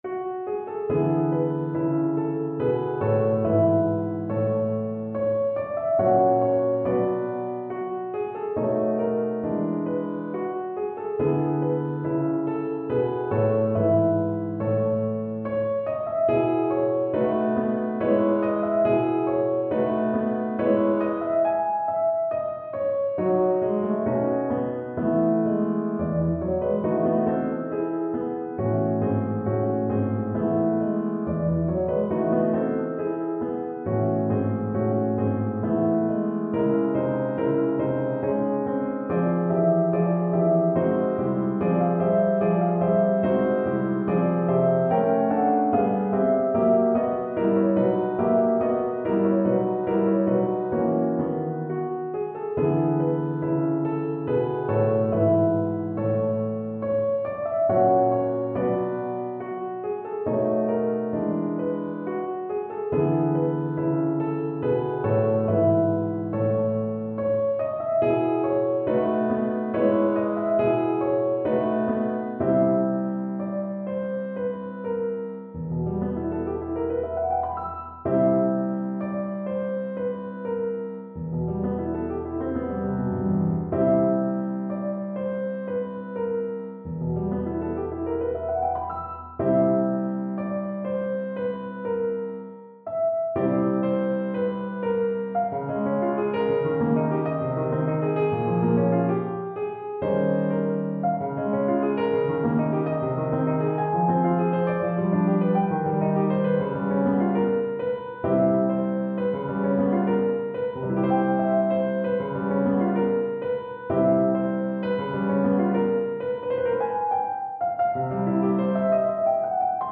Tchaikovsky, Pyotr Ilyich - The Seasons, Op.37a (Complete) Free Sheet music for Piano
Classical Piano
solo piano